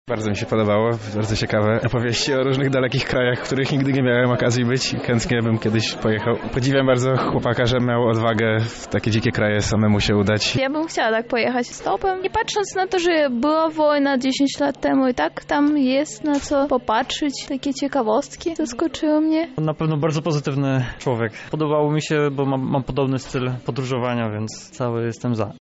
Po prezentacji zapytaliśmy uczestników o wrażenia: